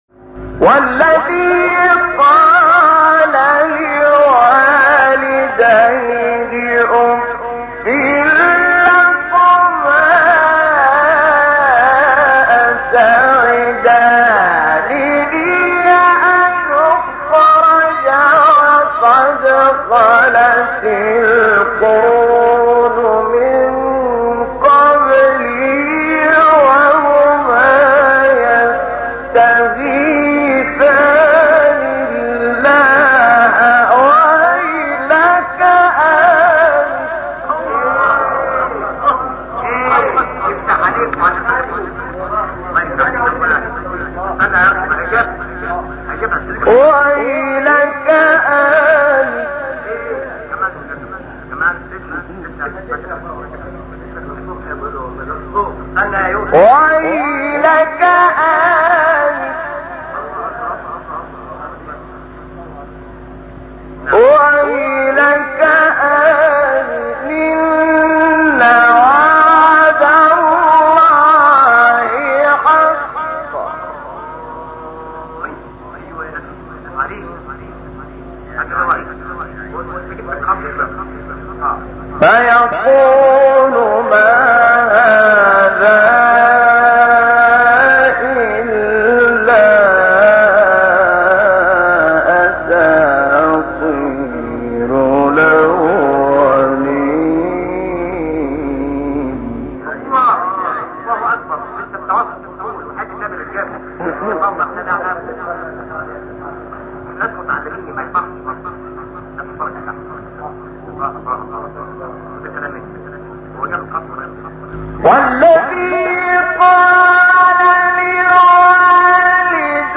تلاوت 17 احقاف مقام رست استاد شحات | نغمات قرآن
سوره : احقاف آیه: 17 استاد : شحات مقام : رست ( در چند فراز از مقام نهاوند استفاده شده است) قبلی بعدی